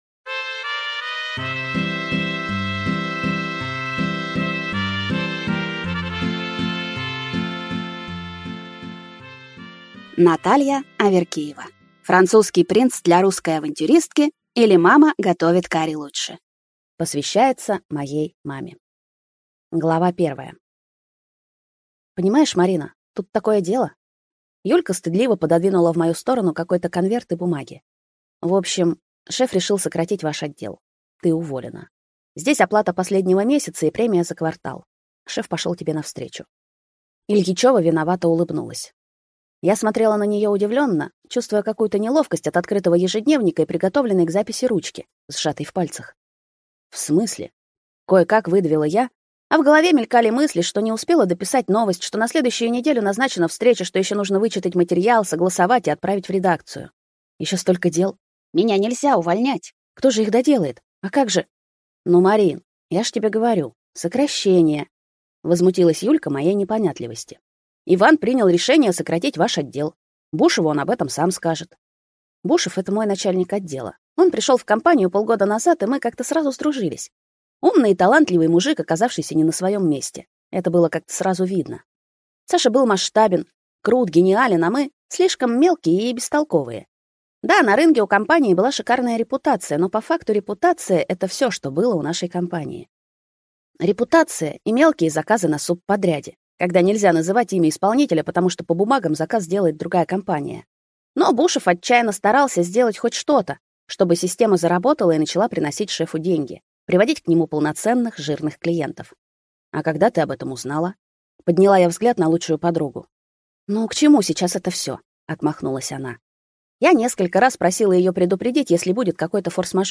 Аудиокнига Французский принц для русской авантюристки, или Мама готовит карри лучше | Библиотека аудиокниг